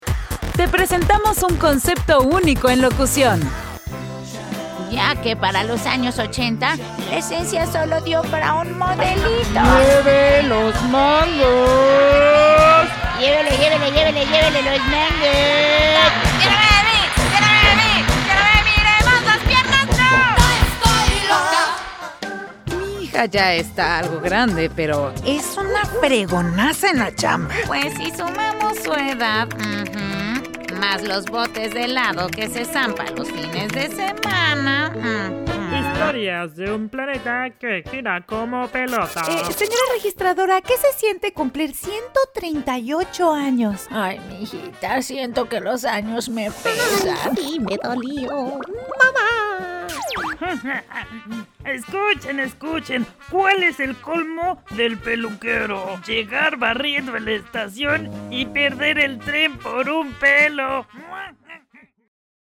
I have a clear voice and the ability to use various tones, accents, and emotions to convey material properly.
broadcast level home studio